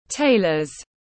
Tailor /ˈteɪ.lər/